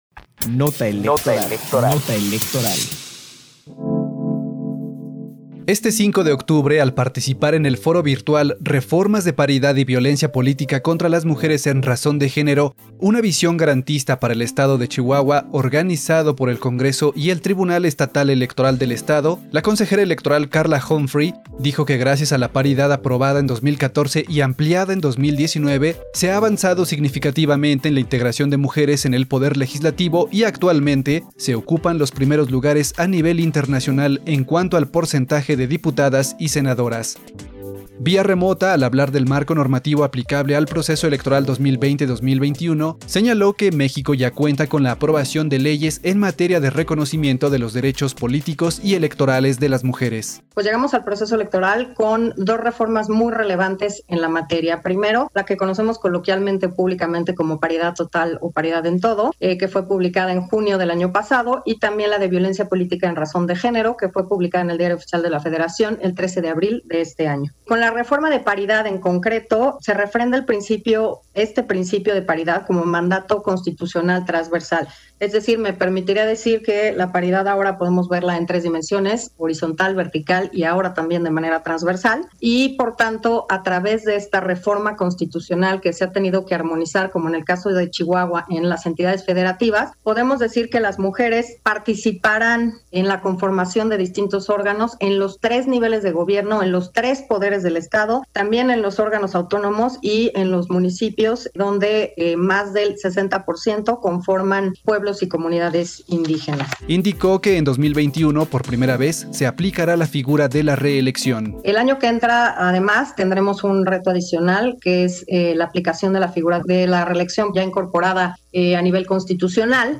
Foro virtual Reformas de paridad y violencia política contra las mujeres en razón de género: Una visión garantista para el Estado de Chihuahua